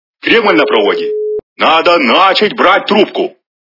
» Звуки » Люди фразы » Голос - Кремль на проводе
При прослушивании Голос - Кремль на проводе качество понижено и присутствуют гудки.